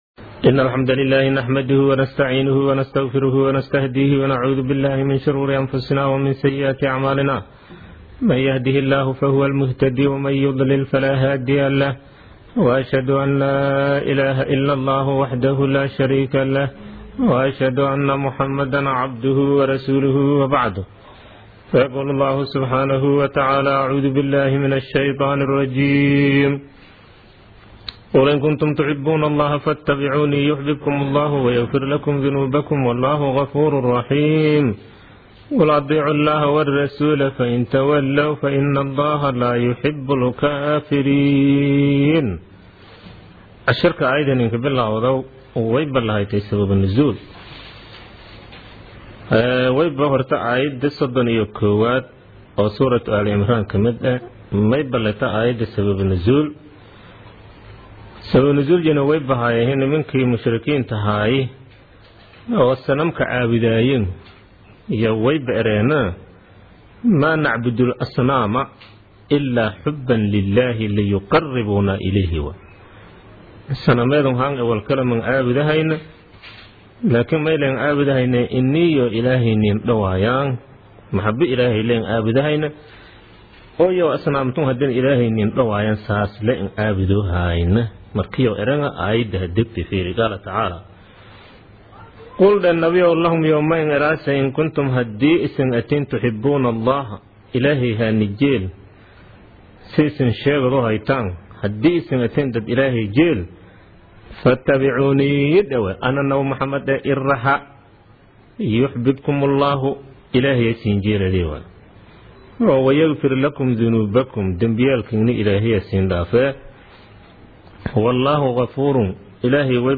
Casharka Tafsiirka Maay 42aad
Casharka-Tafsiirka-Maay-42aad.mp3